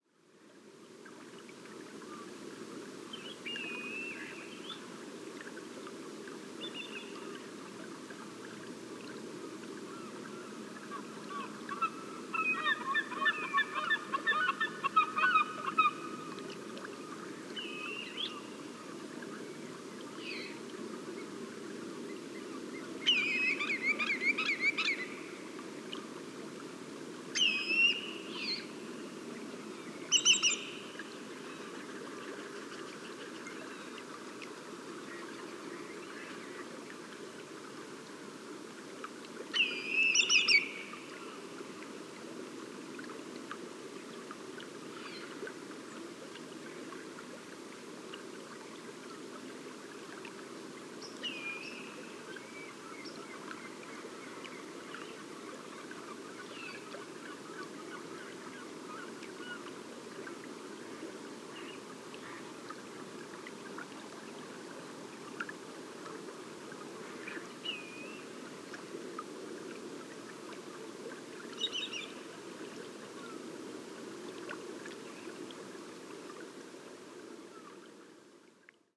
Pluvier argenté & Grand Chevalier – Pluvialis squatarola & Tringa melanoleuca
Écouter les cris de ces limicoles aux premières lueurs du jour, observer les oiseaux marins en migration traverser l’horizon, respirer l’air salin de marée basse et sentir le froid annonçant la fin de l’été.
Parc nature de Pointe-aux-Outardes, QC, 49°02’32.0″N 68°27’17.7″W. 6 septembre 2018. 5h20. marée basse.
* Les vocalisations de deux espèces se chevauchent sur cet enregistrement. Le cri ‘Pyowee’ du Pluvier argenté ainsi que le chant et le cri ‘Klee’ du Grand Chevalier.